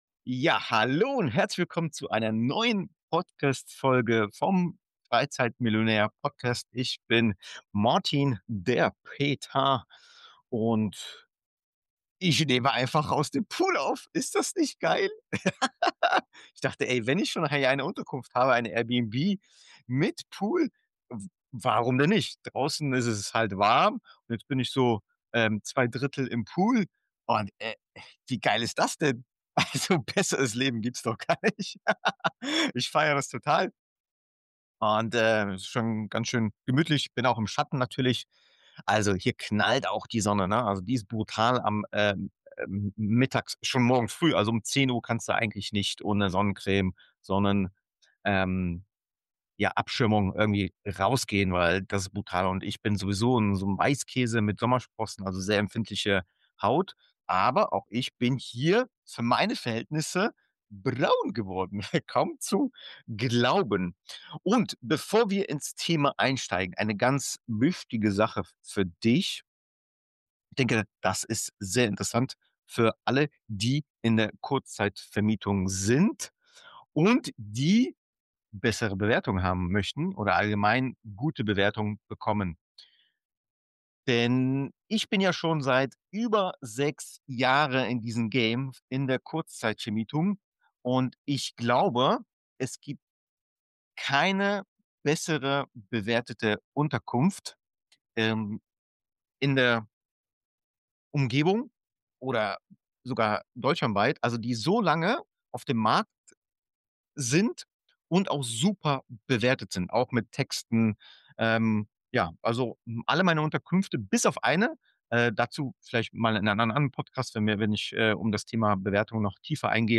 ------------- Ich nehme diese Folge aus dem Pool auf – mitten aus dem Leben.